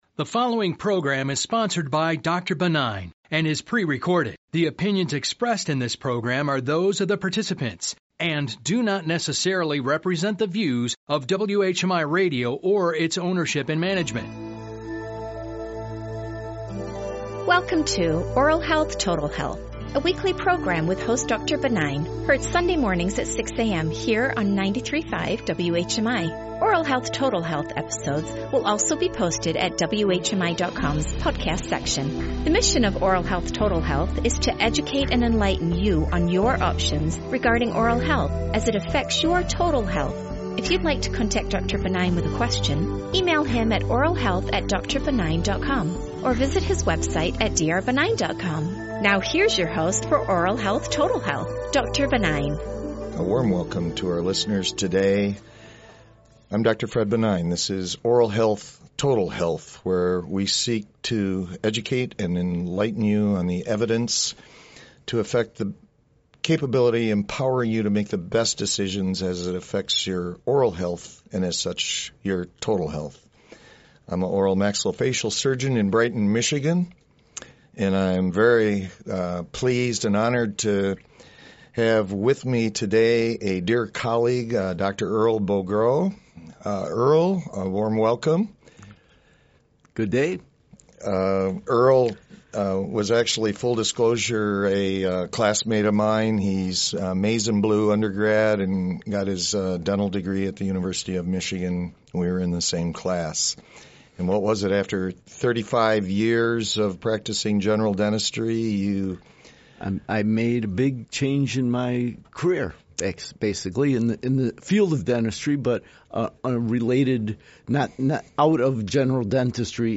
broadcast
Enjoy other podcasts Click the play button at the right to listen in on this informative discussion.